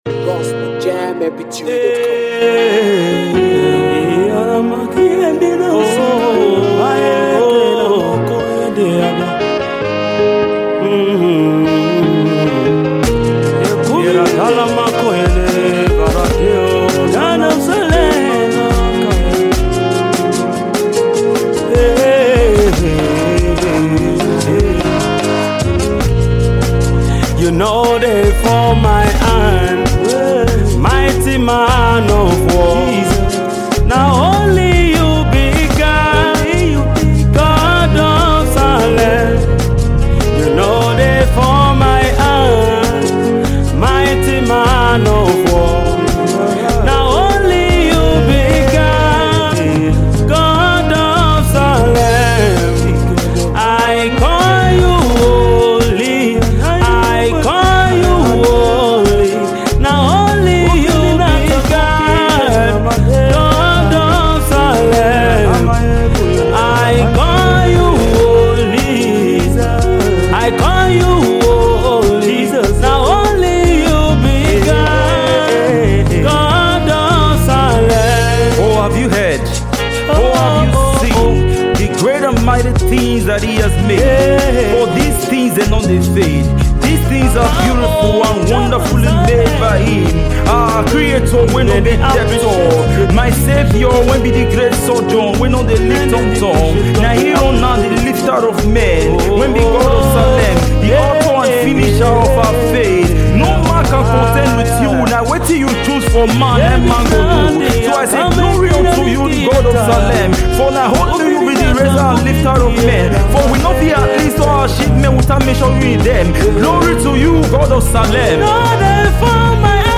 Gospel singer